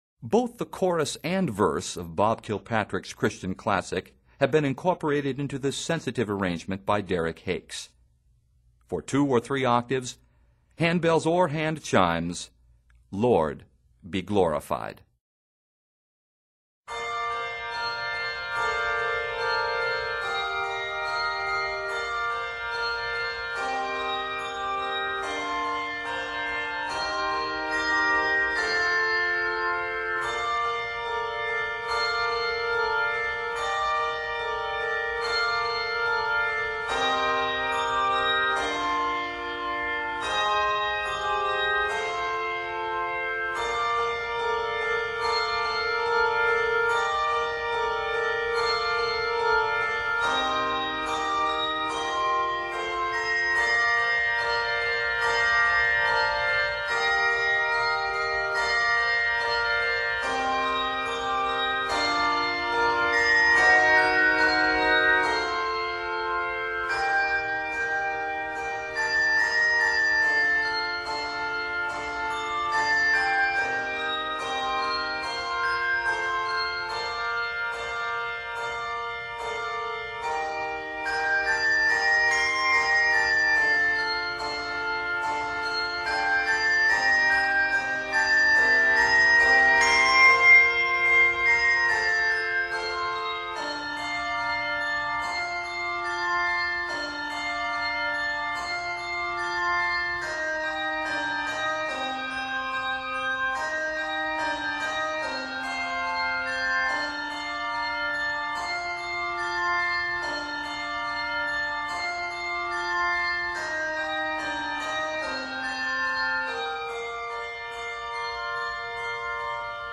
contemporary Christian classic
touching and prayerful arrangement
Set in Bb and C Major, measures total 55.